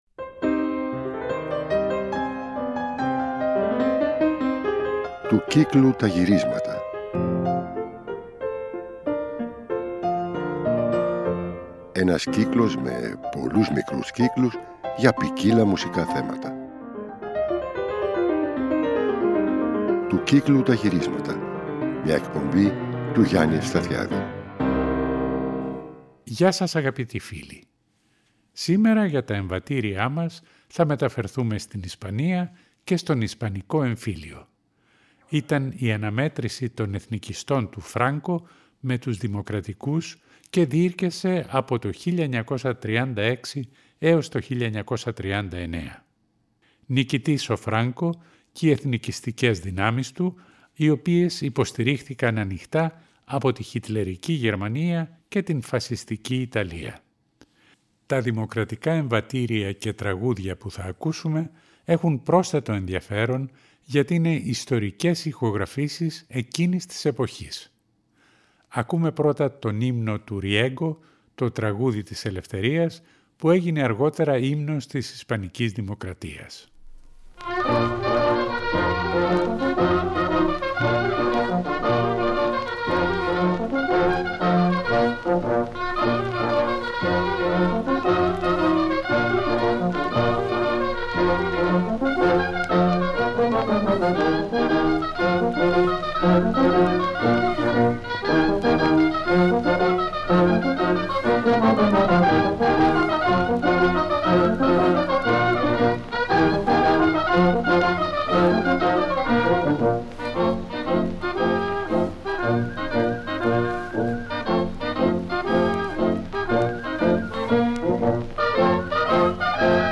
Αφιέρωμα στα Εμβατήρια (18ο Μέρος)
Στη σημερινή εκπομπή ακούγονται, μεταξύ άλλων, εμβατήρια από την περίοδο του Ισπανικού εμφυλίου πολέμου (1936 -1939) σε ιστορικές και πρωτότυπες ηχογραφήσεις.